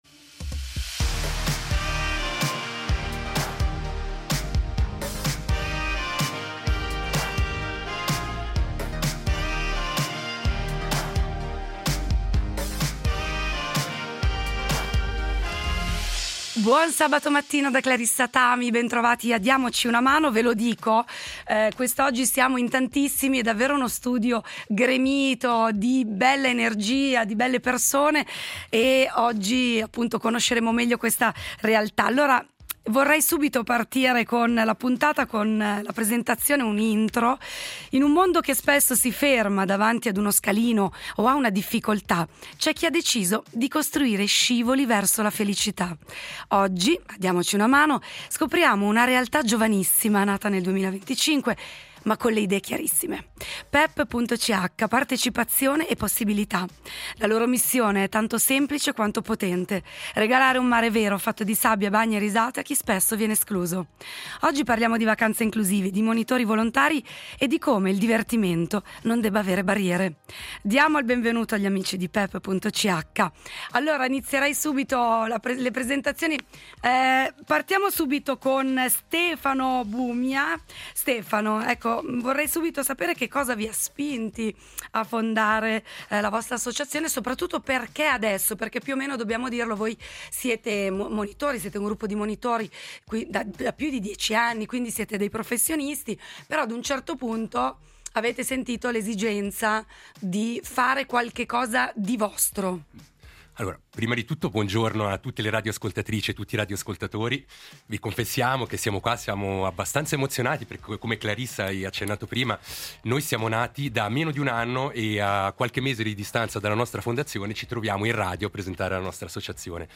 In studio le voci dell’ Associazione PeP .